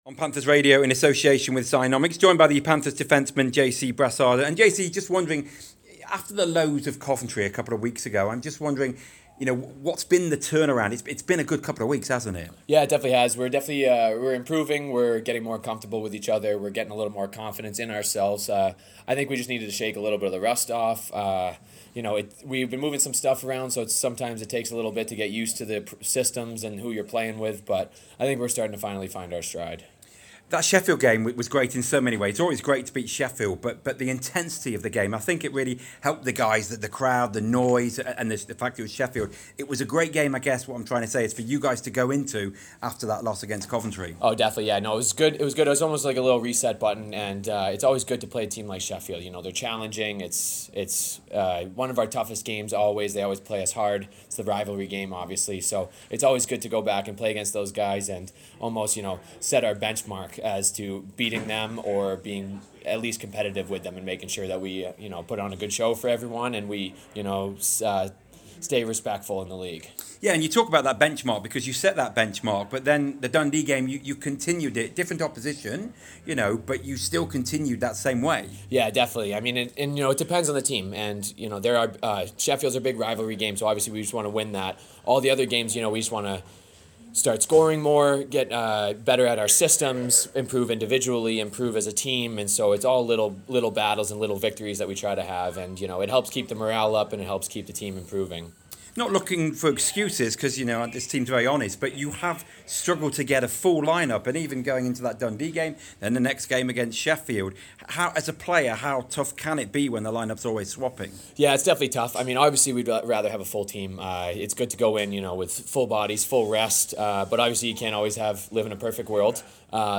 talks with Panthers Radio ahead of this weekend's action in the Premier Sports Elite League against Cardiff Devils and Fife Flyers.